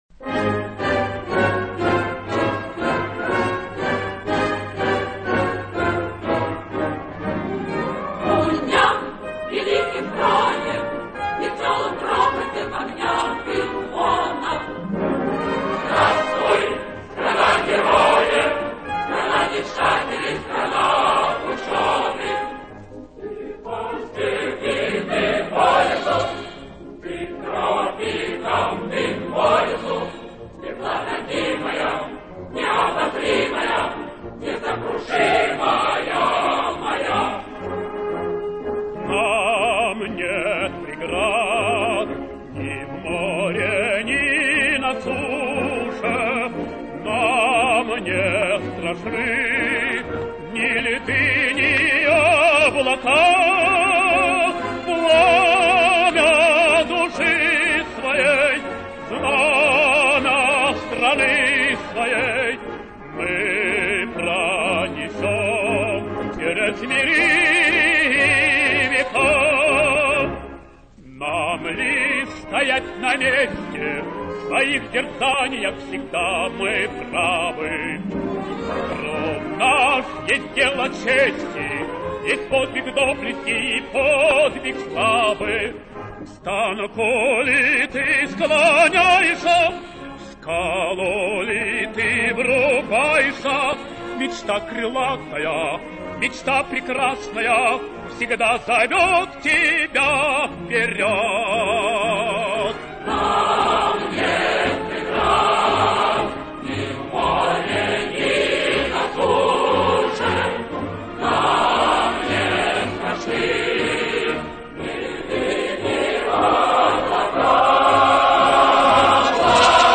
Версия известнейшей песни в лучшем качестве